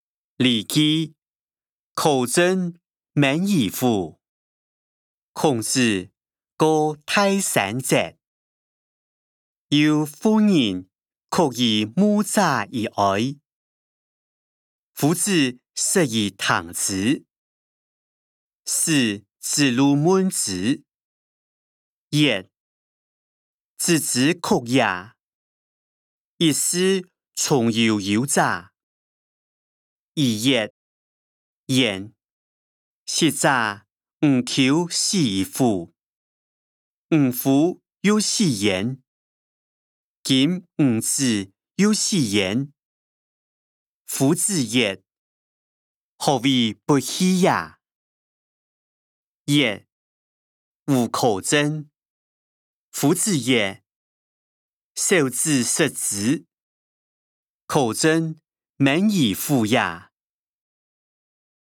經學、論孟-苛政猛於虎音檔(四縣腔)